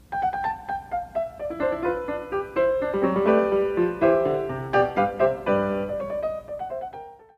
Uitgevoerd door Arturo Benedetti Michelangeli.